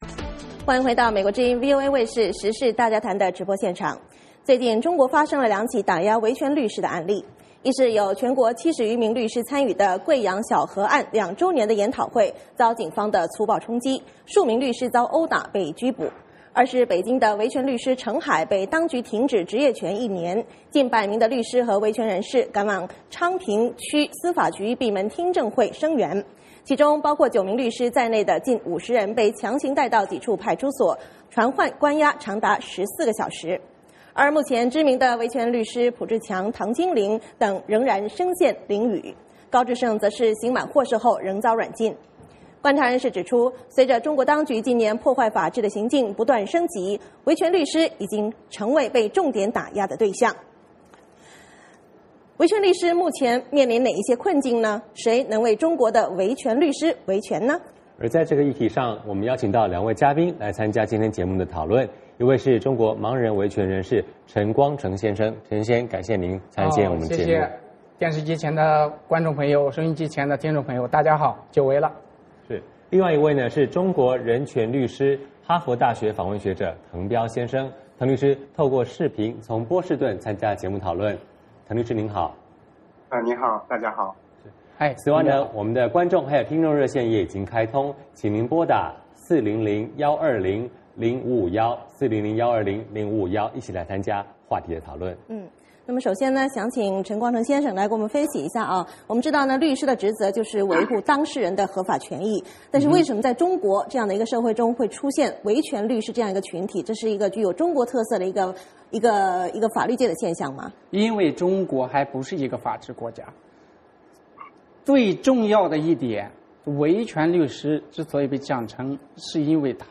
欢迎回到美国之音VOA卫视时事大家谈的直播现场。